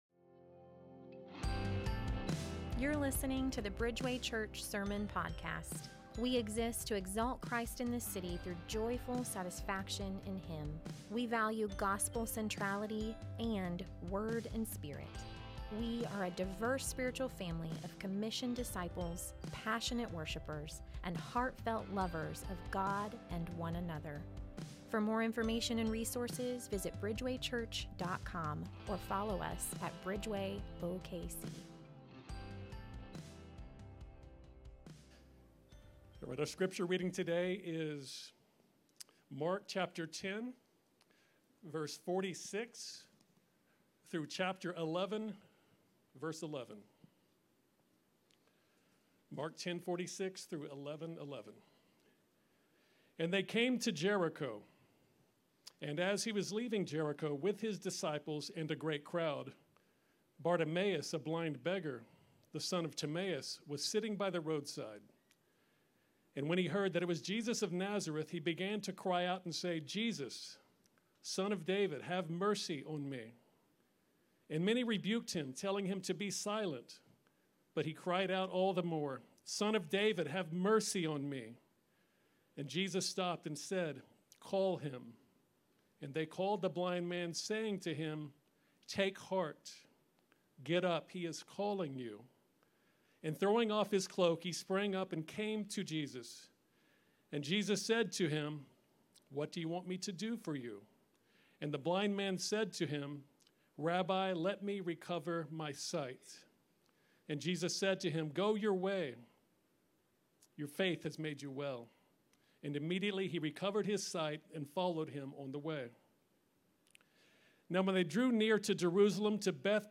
january-5-2025-sermon.m4a